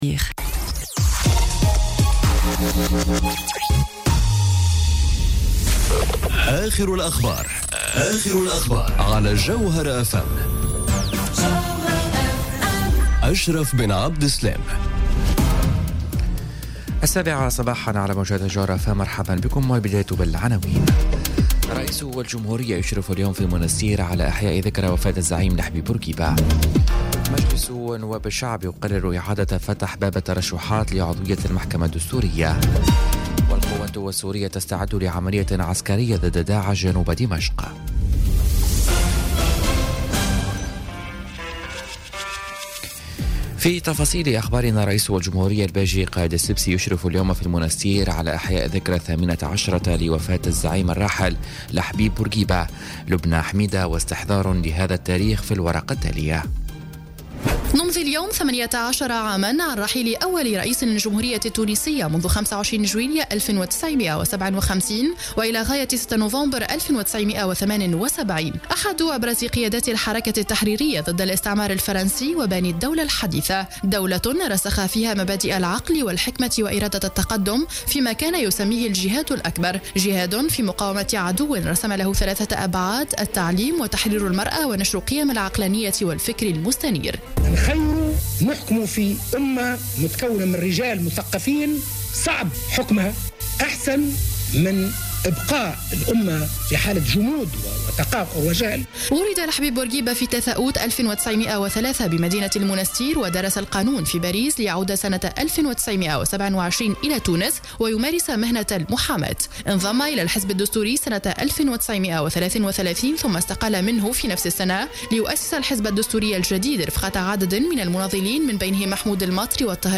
نشرة أخبار السابعة صباحا ليوم الجمعة 6 أفريل 2018